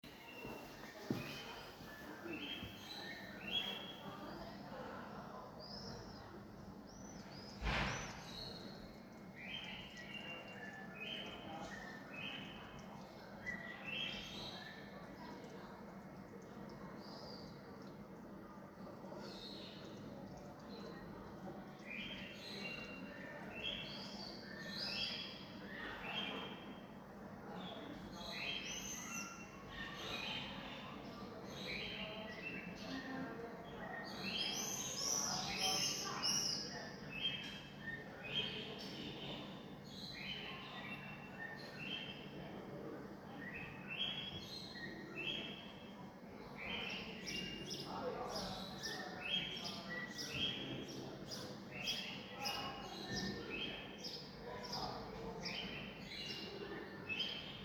Baby Blackbird Sounds and Communication
Even before they can fly, baby blackbirds vocalize with high-pitched begging calls. This sound cues the parents to deliver food.